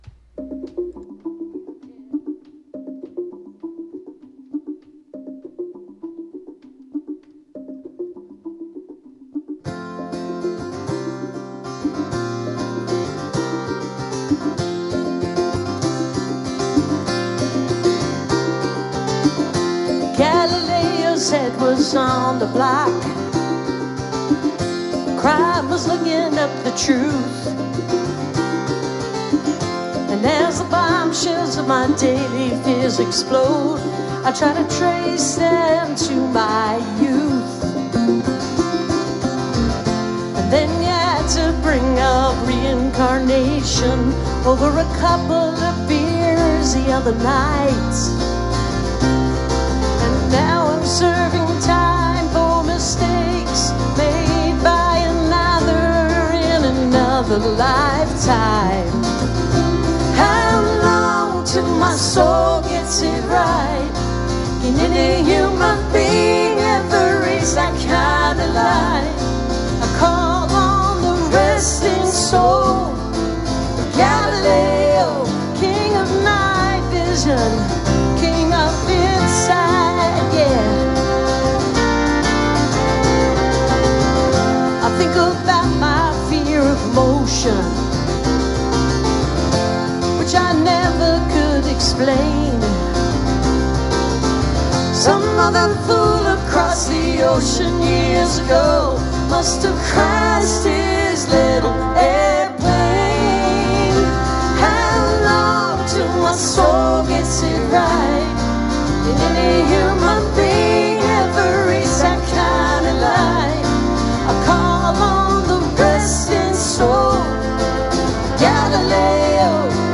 (live summit soundcheck live version)